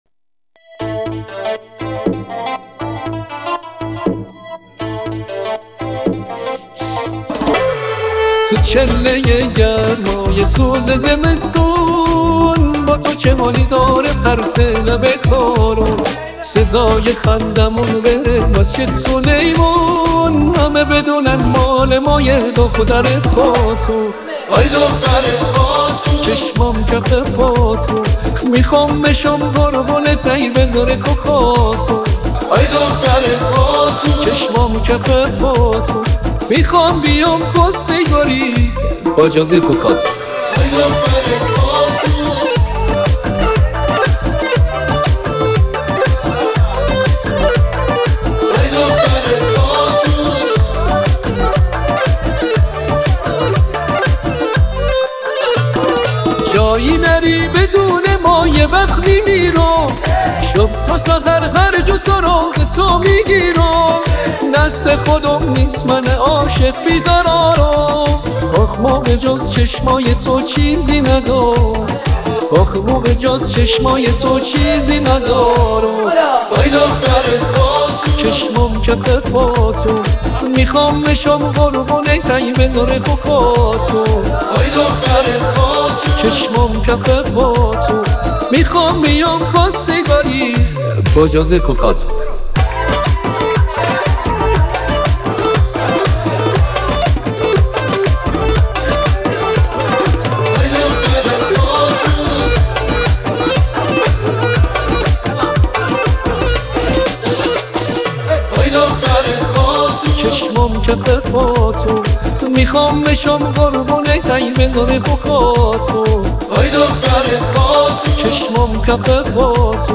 Iranian music
Sabk: Talfighi az Musighi Pop Irani va Sonati